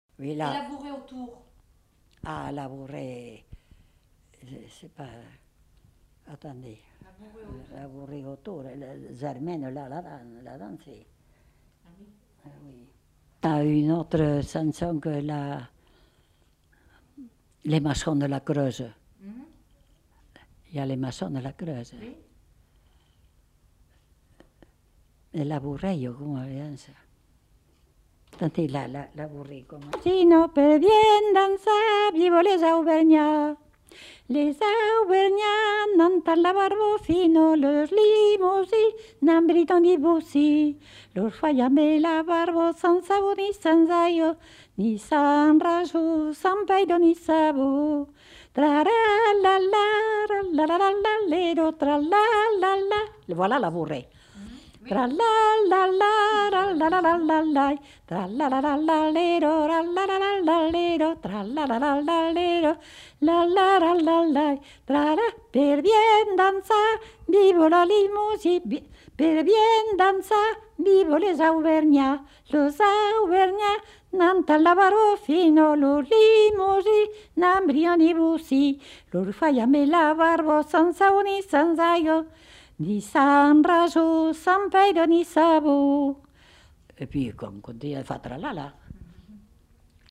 Lieu : Condezaygues
Genre : chant
Effectif : 1
Type de voix : voix de femme
Production du son : chanté ; fredonné
Descripteurs : bourrée
Classification : danses